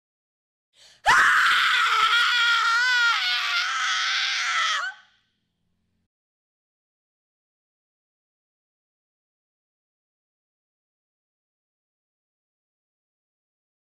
دانلود صدای جیغ زن ترسناک 3 از ساعد نیوز با لینک مستقیم و کیفیت بالا
جلوه های صوتی